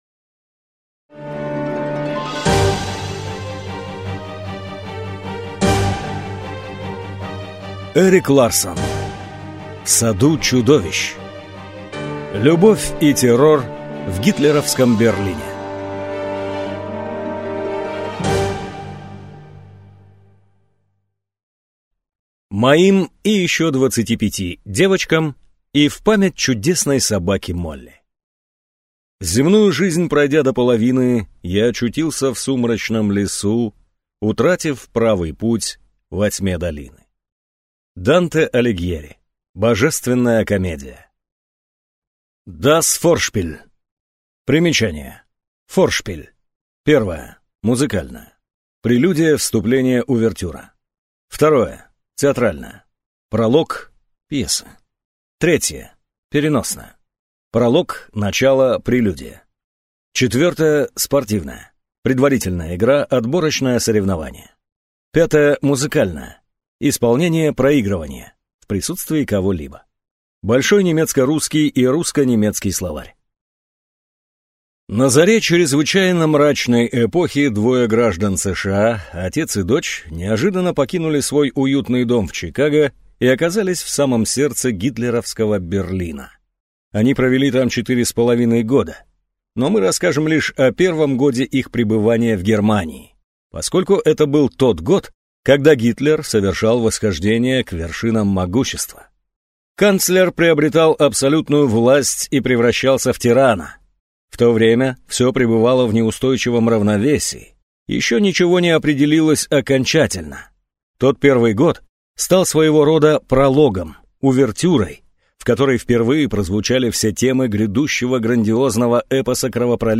Аудиокнига В саду чудовищ. Любовь и террор в гитлеровском Берлине | Библиотека аудиокниг